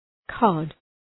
{kɒd}
cod.mp3